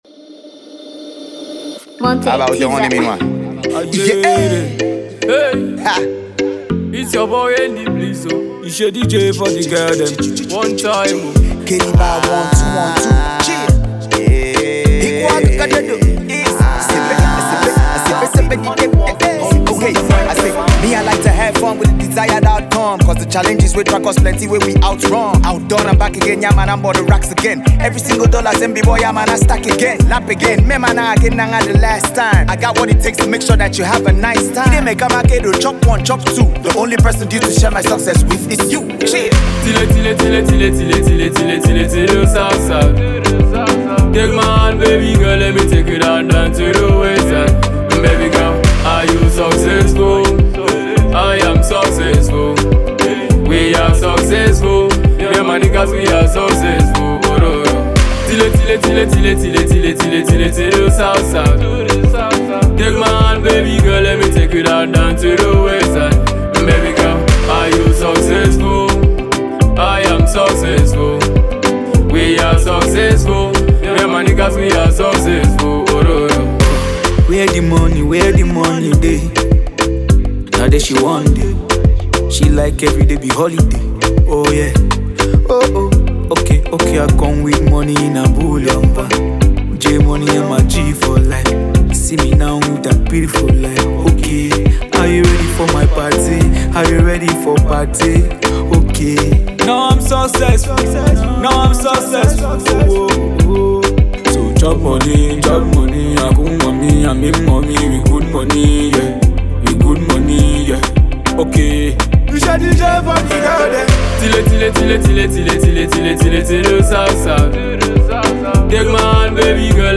refix
another awesome cool tempo jam